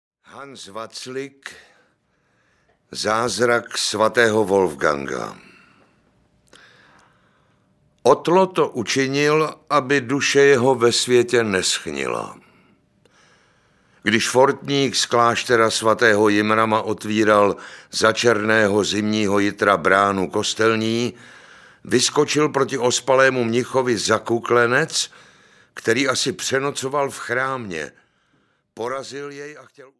Zázrak svatého Wolfganga audiokniha
Ukázka z knihy
zazrak-svateho-wolfganga-audiokniha